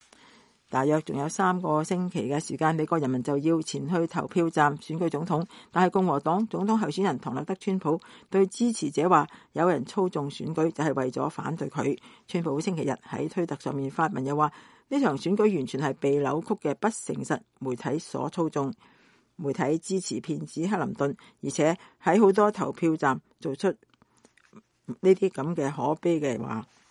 共和黨總統候選人唐納德川普週六對支持者講話。